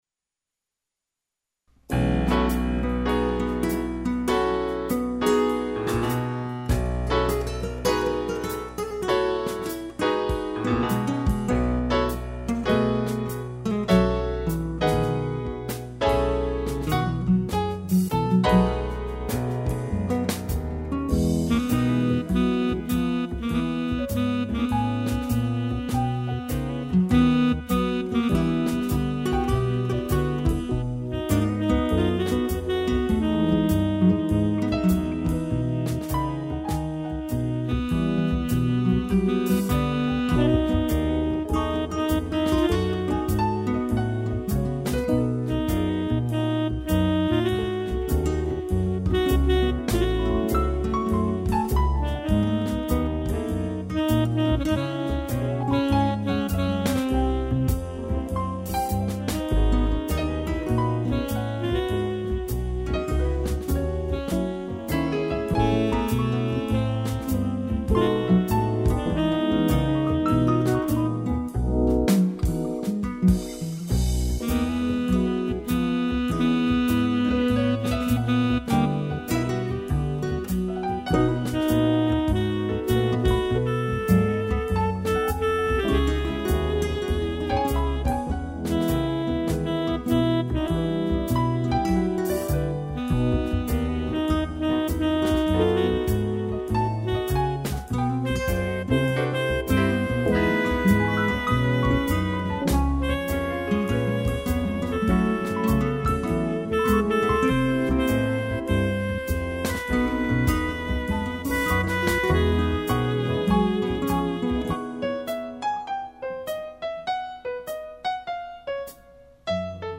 violão
piano
instrumental